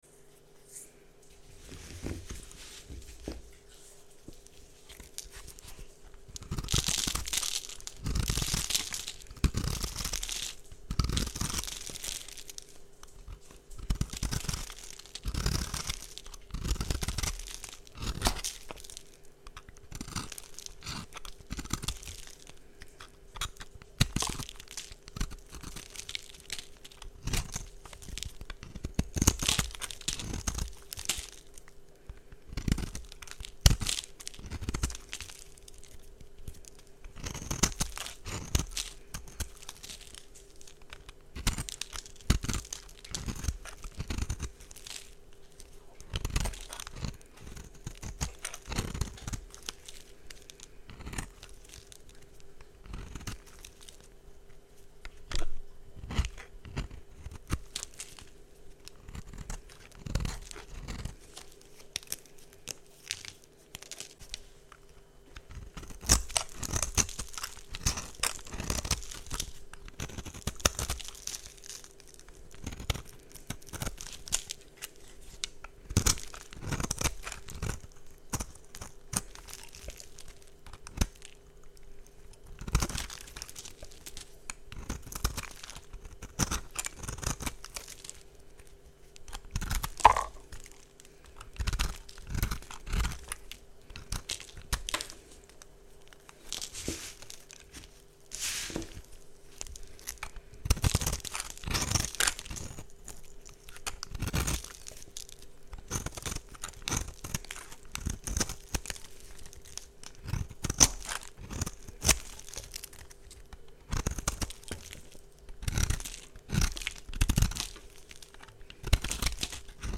Recycled soap crunch